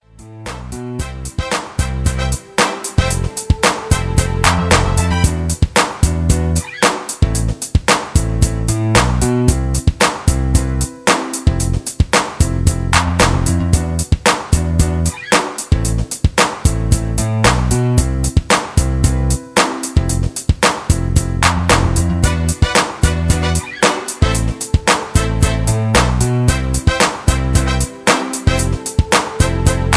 (Key-Em)
Just Plain & Simply "GREAT MUSIC" (No Lyrics).